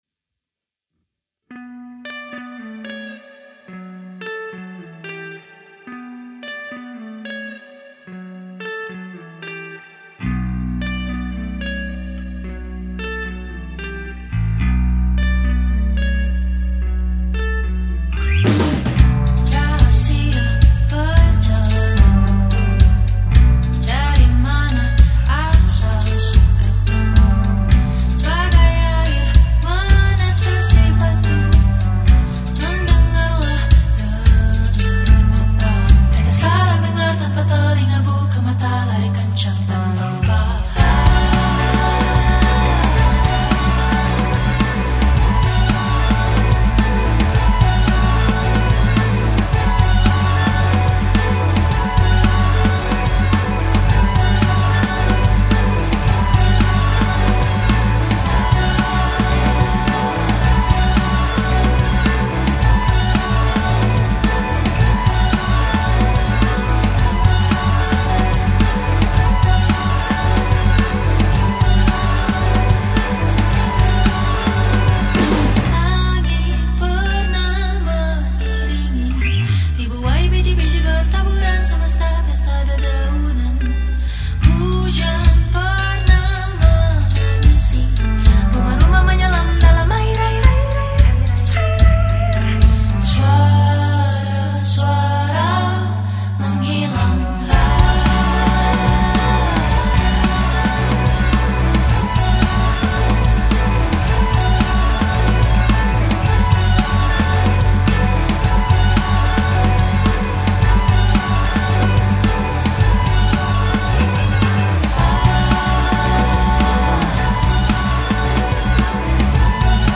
Alternative • Makassar